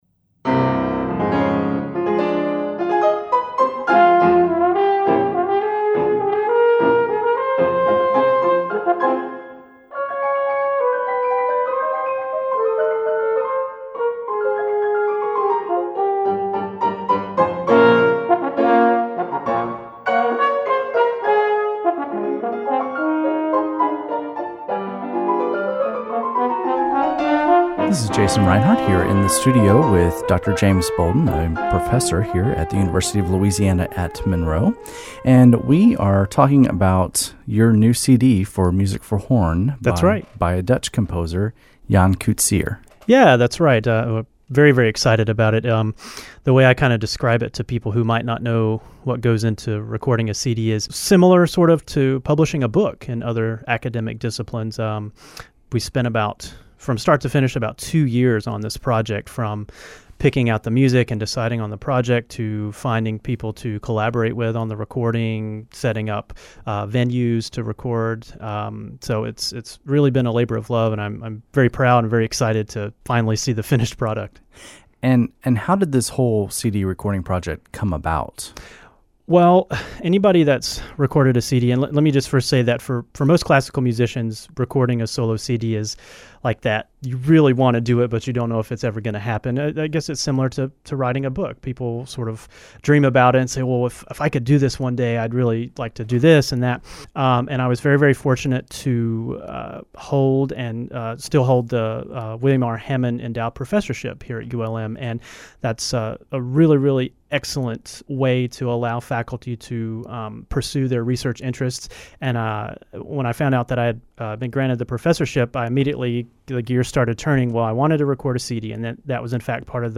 Radio Interview Promo for My New CD: Here’s a brief interview with our local public radio station (90.3 KEDM) discussing my new solo CD. There are some nice excerpts here from the CD that you won’t find anywhere else!